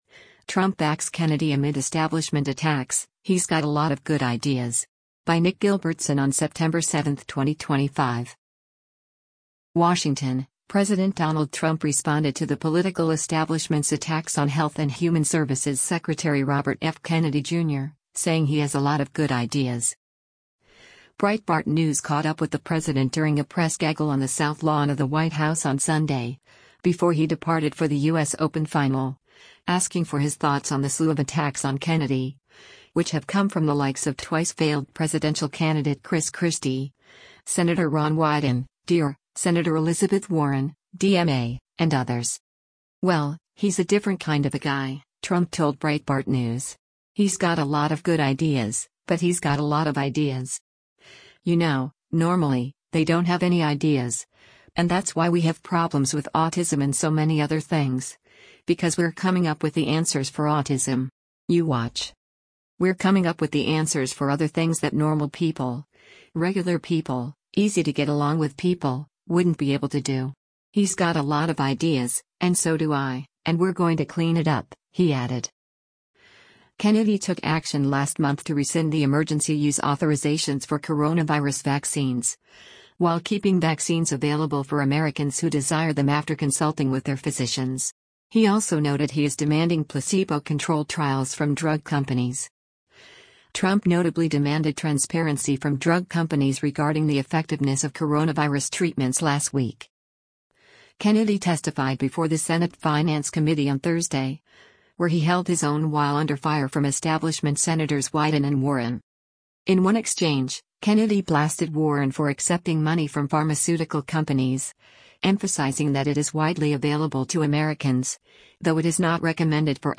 Breitbart News caught up with the president during a press gaggle on the South Lawn of the White House on Sunday, before he departed for the U.S. Open final, asking for his thoughts on the slew of attacks on Kennedy, which have come from the likes of twice-failed presidential candidate Chris Christie, Sen. Ron Wyden (D-OR), Sen. Elizabeth Warren (D-MA), and others.